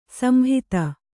♪ samhita